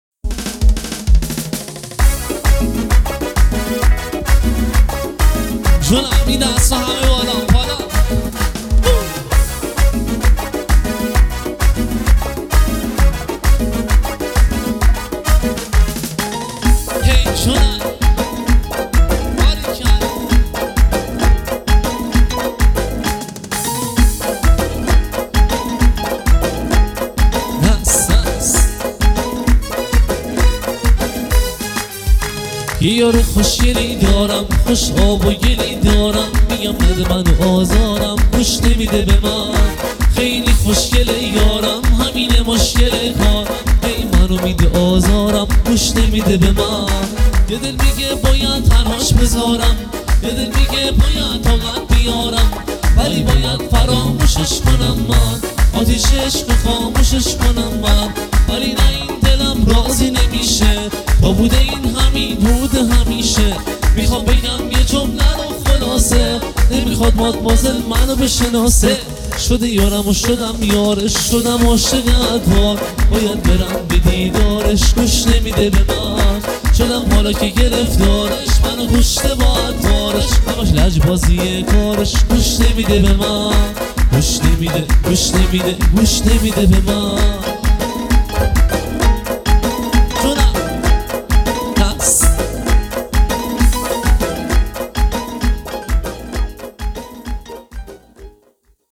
آهنگ ارکستی